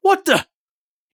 gun_jam_5.ogg